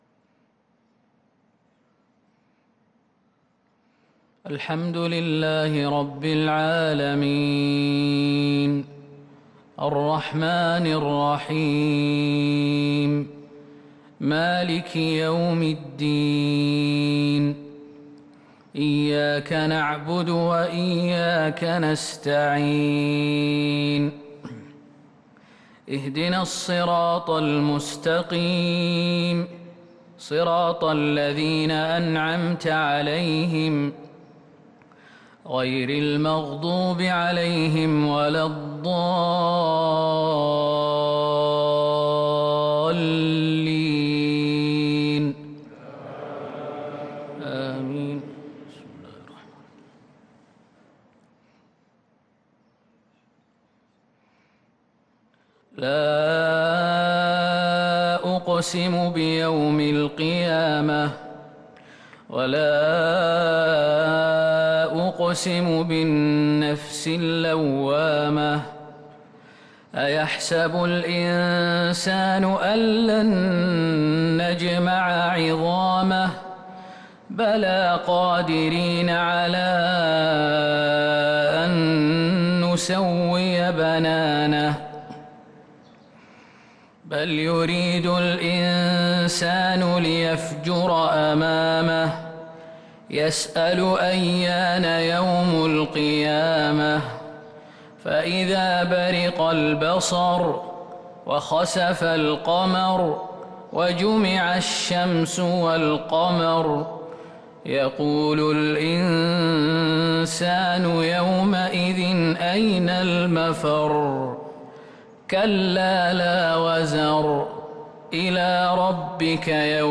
صلاة الفجر للقارئ خالد المهنا 17 رمضان 1443 هـ